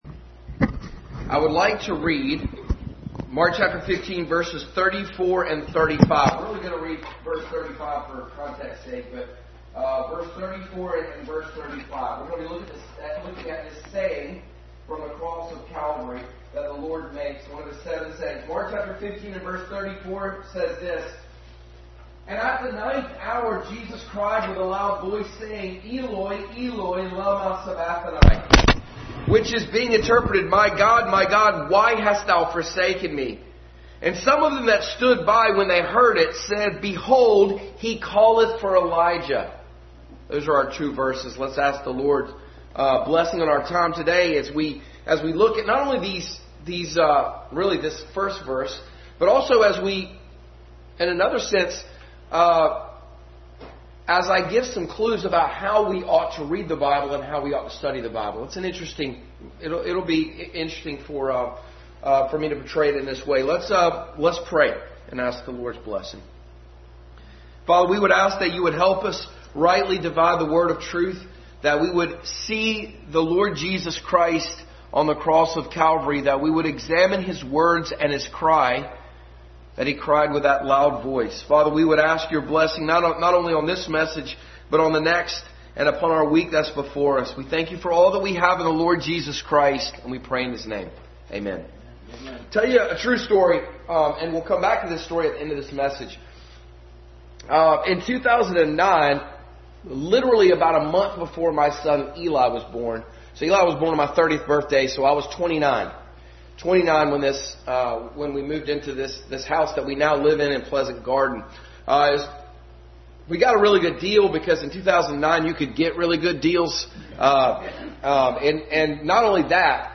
The Double Double Passage: Mark 15:34-35, Acts 9:4, John 3:3-5, Luke 22:15, 31, 10:39-42, 15:1-4, Mark 4, Psalm 22 Service Type: Sunday School